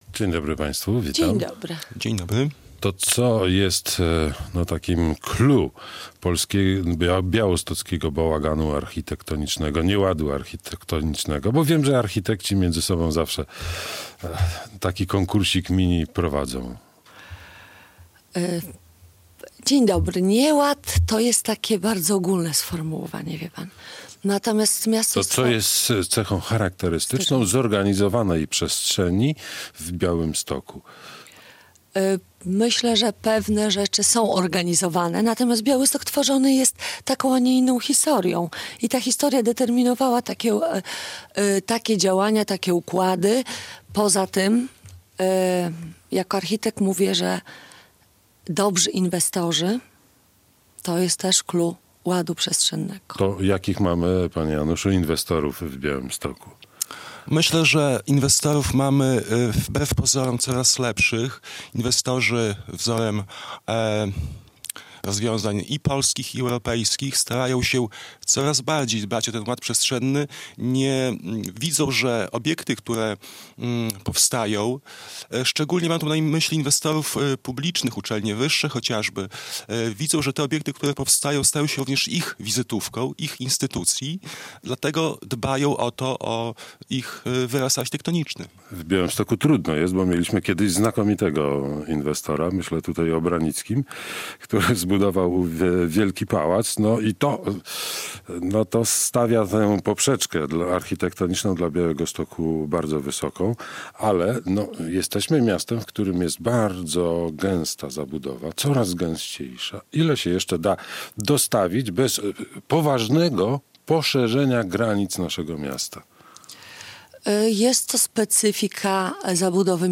architekci